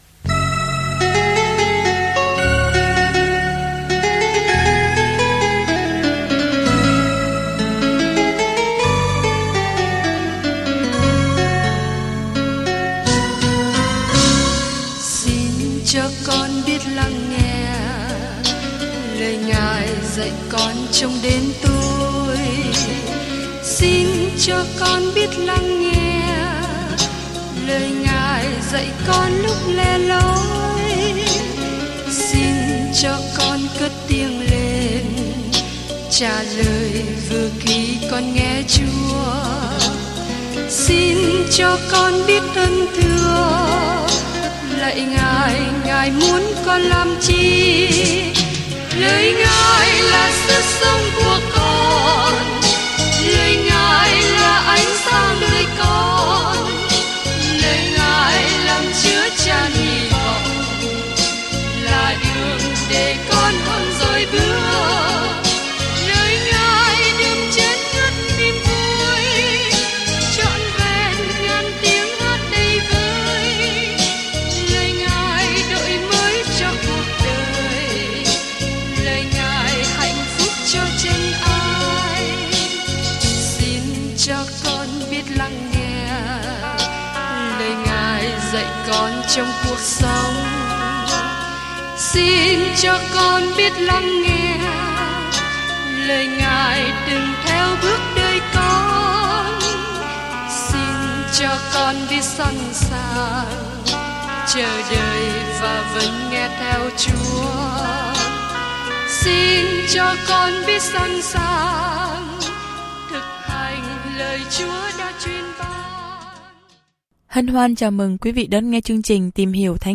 Kinh Thánh II Cô-rinh-tô 4:10-18 II Cô-rinh-tô 5:1-9 Ngày 4 Bắt đầu Kế hoạch này Ngày 6 Thông tin về Kế hoạch Niềm vui của các mối quan hệ trong thân thể Chúa Kitô được nhấn mạnh trong lá thư thứ hai gửi tín hữu Cô-rinh-tô khi bạn nghe đoạn ghi âm nghiên cứu và đọc những câu chọn lọc từ lời Chúa. Du lịch hàng ngày qua 2 Cô-rinh-tô khi bạn nghe nghiên cứu âm thanh và đọc những câu chọn lọc từ lời Chúa.